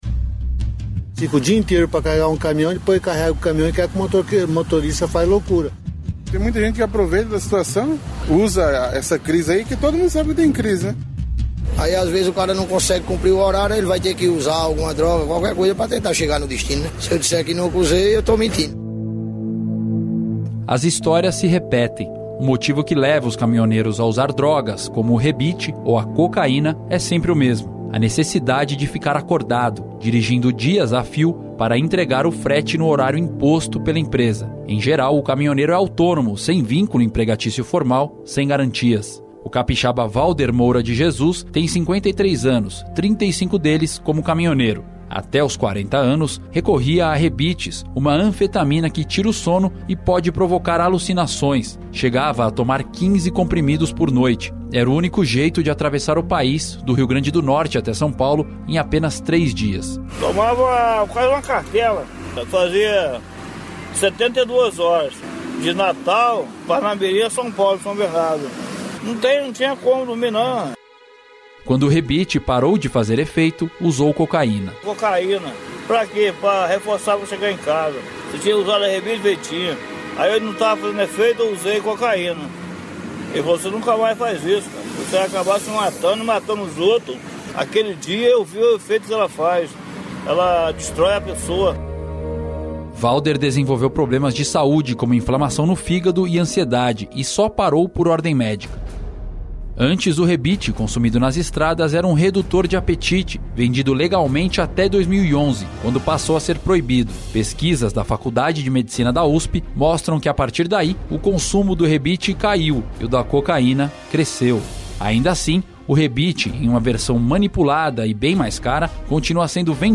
Na última reportagem, desta sexta-feira (24), o jornalista entrevistou caminhoneiros que relatam os motivos que os levam a usar entorpecentes. Eles também contam as experiências de vida e morte provocadas pela dependência química.
Reportagem 3: